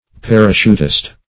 parachuter \par"a*chut`er\, parachutist \par`a*chut"ist\, n.